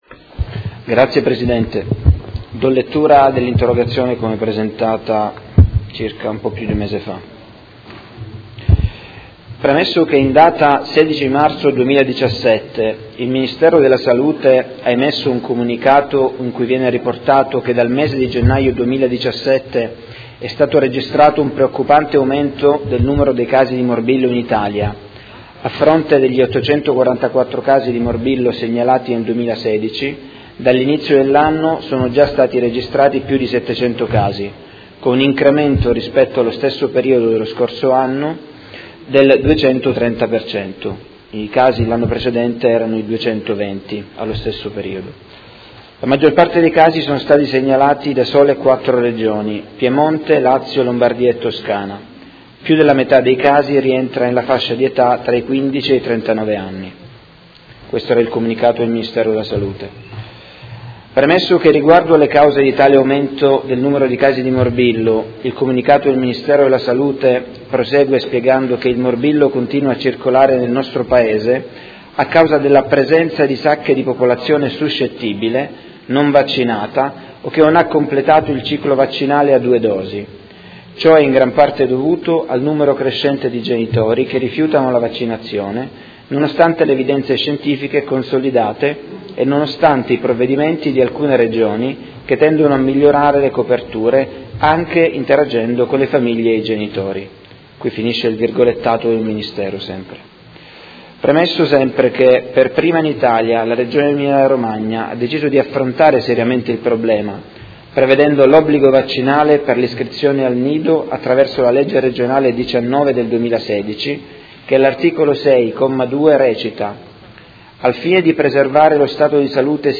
Seduta del 04/05/2017. Interrogazione dei Consiglieri Fasano e Venturelli (PD) avente per oggetto: Misure a sostegno di una completa copertura vaccinale e contrasto alla disinformazione sul tema